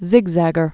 (zĭgzăgər)